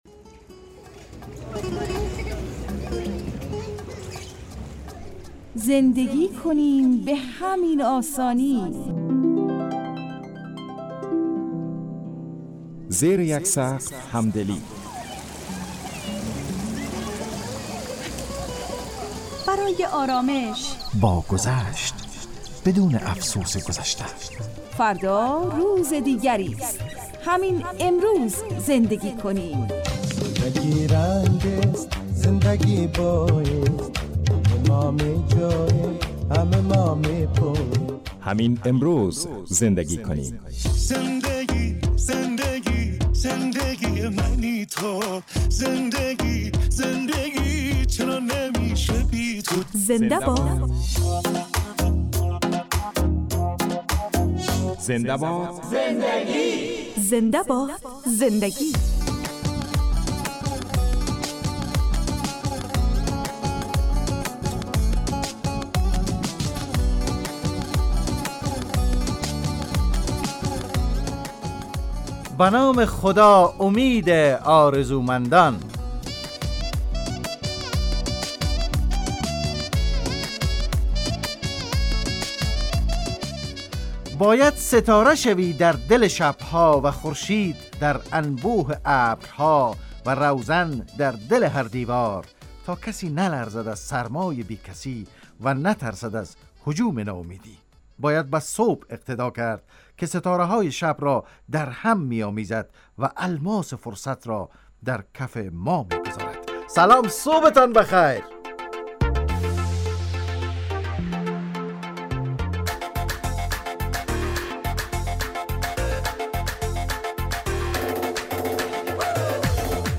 زنده باد زندگی __ برنامه خانوادگی رادیو دری__ زمان پخش ساعت